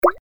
Button_Hit.mp3